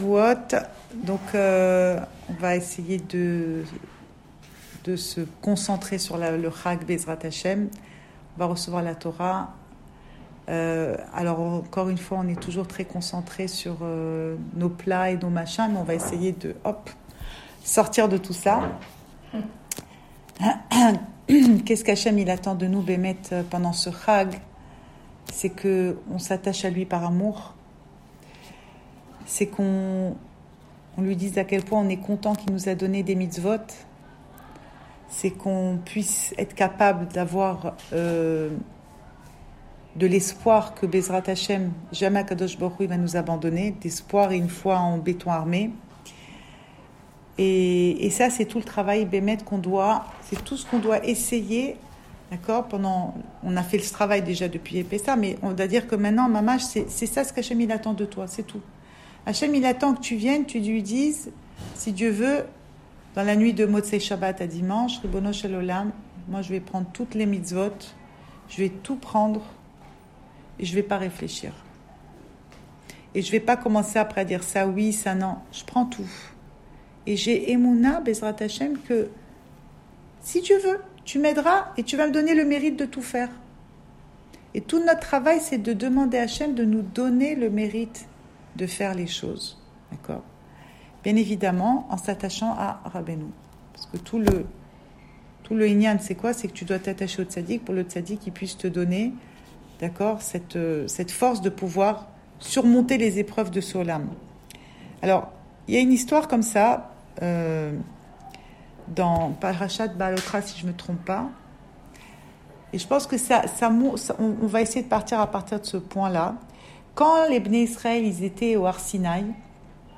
Cours audio Fêtes Le coin des femmes - 4 juin 2019 6 juin 2019 Chavouot : avérot, taavot & folies du coeur. Enregistré à Raanana